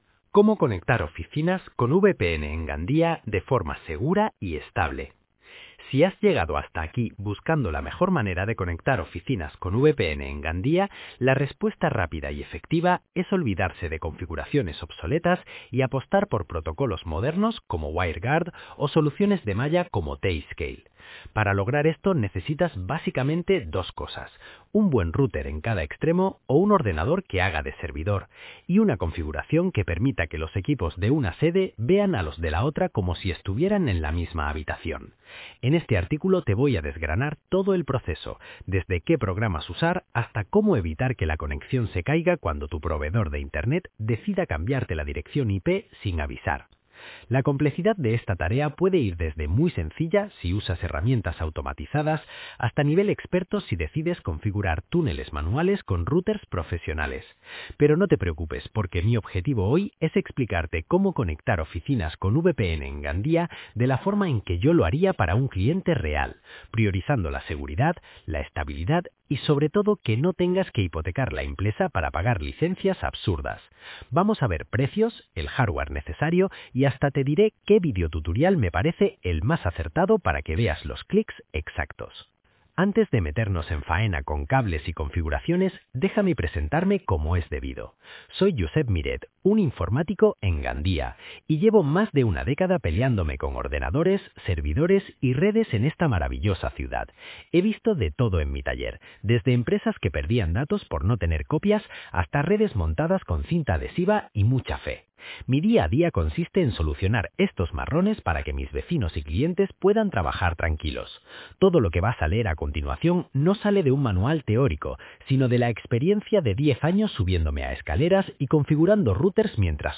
Dale al play para escuchar el artículo Conectar oficinas con VPN en Gandia